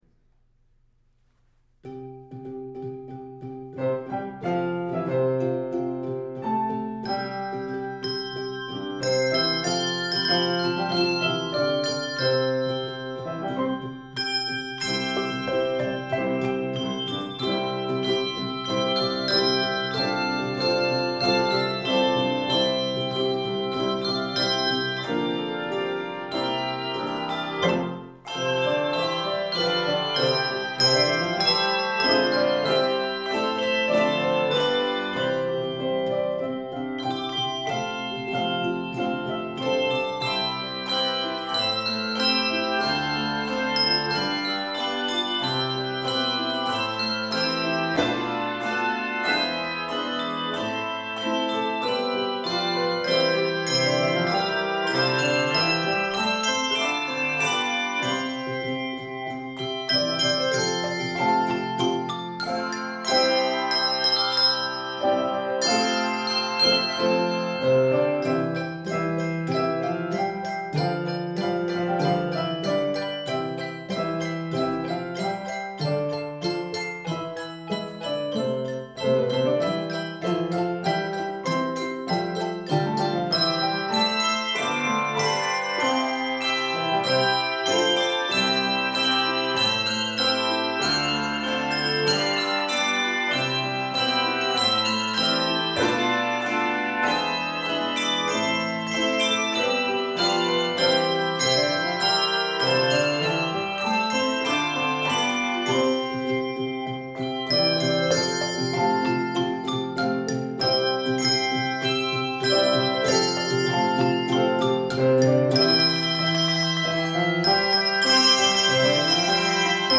upbeat and vibrant
Key of C Major.